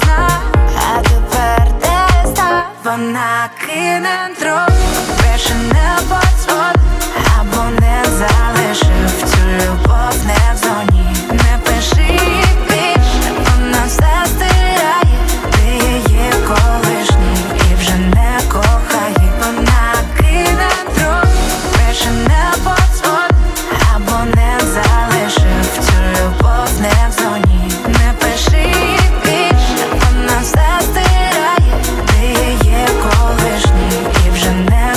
Жанр: Украинские
# Поп